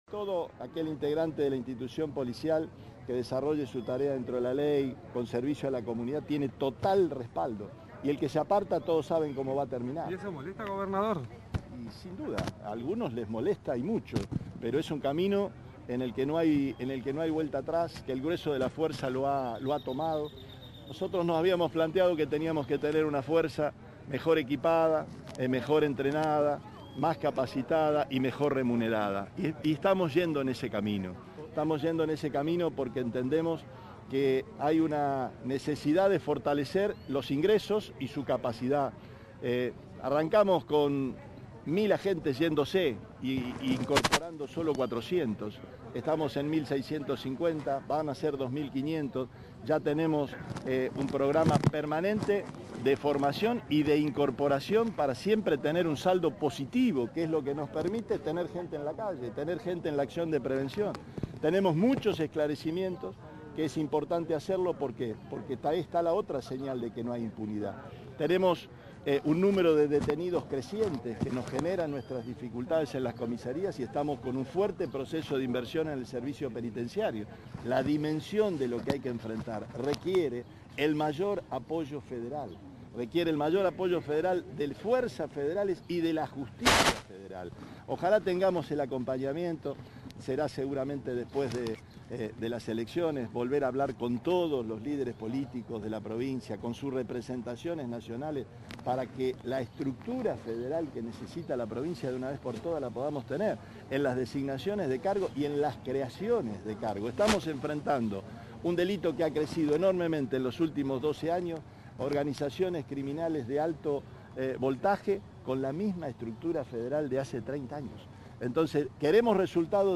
Desde la ciudad capital, el mandatario provincial dijo que Santa Fe necesita del “apoyo de las fuerzas federales y de la Justicia federal” y adelantó que “después de las elecciones hablará con todos los líderes políticos para tener la estructura federal“.